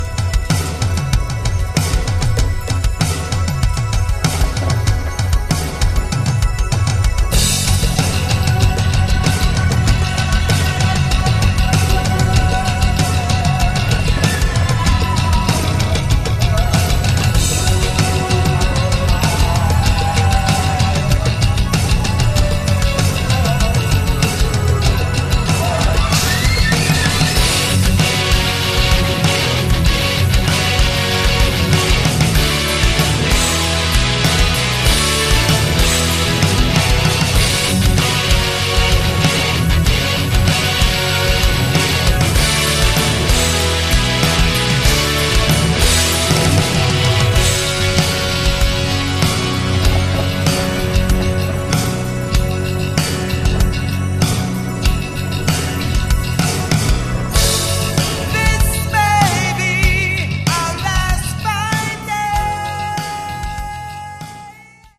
Category: AOR
lead vocals, acoustic guitar
drums, percussion, backing vocals